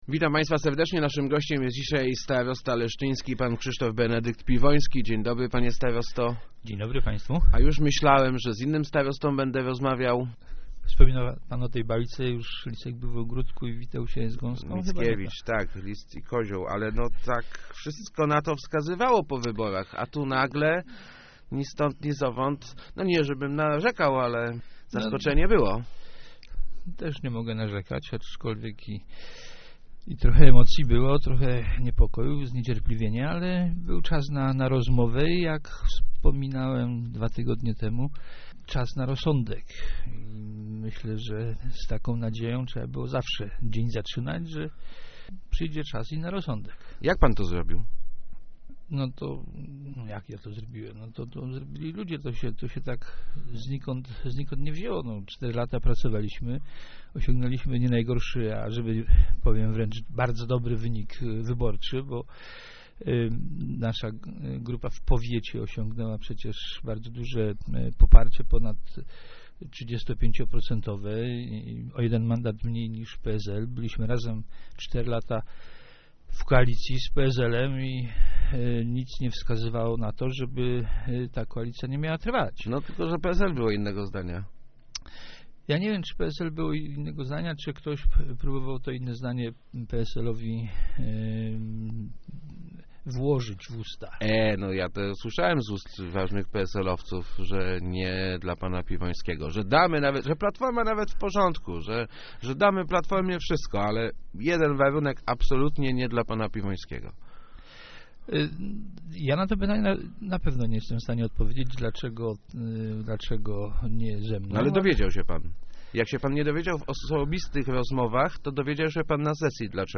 Cieszę się, że w PSL są ludzie, którzy docenili to, co razem robiliśmy przez ostatnie cztery lata - mówił w Rozmowach Elki Krzysztof Piwoński, wybrany na kolejną kadencję starostą powiatu leszczyńskiego.